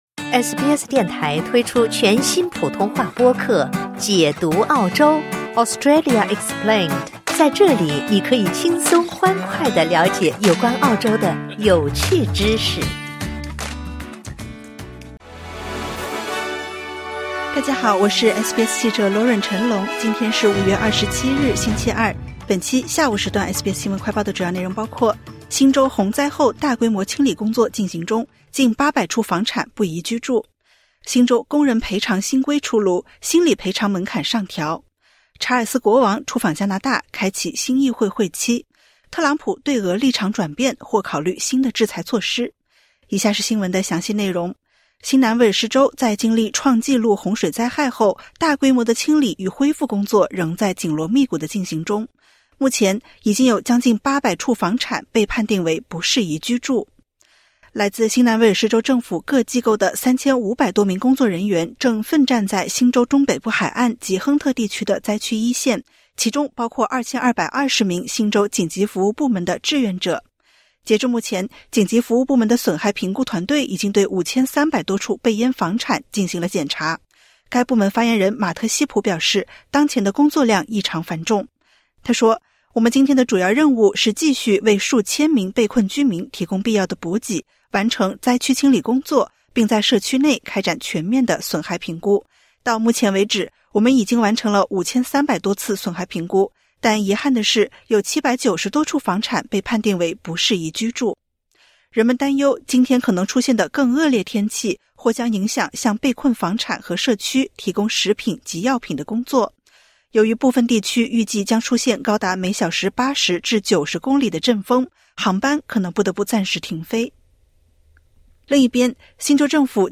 【SBS新闻快报】新州洪灾后大规模清理中 近800处房产不宜居